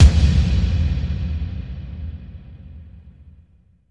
VEC3 FX Reverbkicks 08.wav